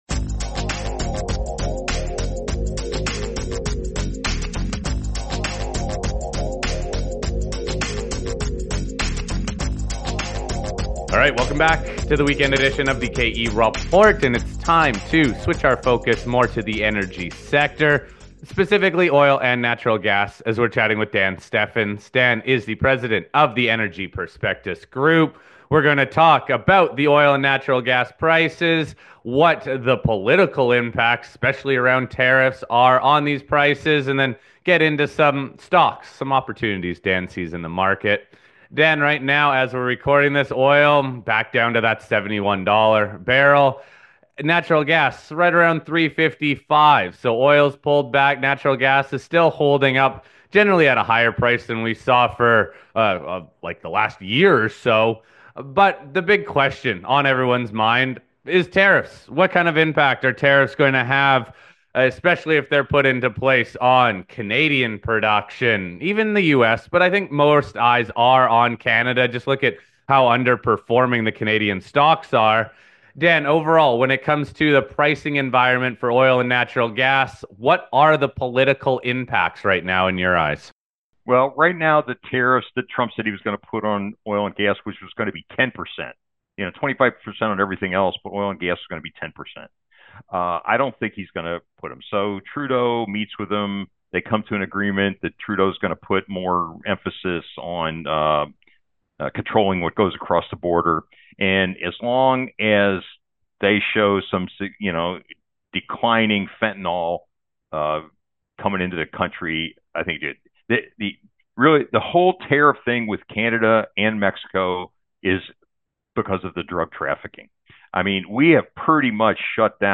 The show also explores current topics at the intersection of economics and politics, and features a fascinating array of economists, writers, and industry experts.